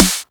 SNARE20.wav